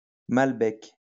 Malbec (French pronunciation: [malbɛk]